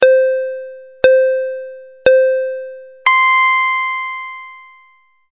倒计时.mp3